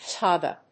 音節tai・ga 発音記号・読み方
/tάɪgə(米国英語)/